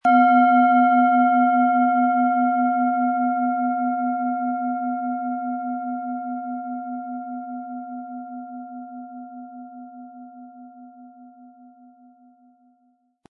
Planetenschale® Lebenslustig sein & Kreativ sein mit Delfin-Ton & Jupiter, Ø 13,7 cm, 260-320 Gramm inkl. Klöppel
Von Hand getriebene Schale mit dem Planetenton Delfin.
PlanetentöneDelphin & Jupiter
SchalenformBihar
MaterialBronze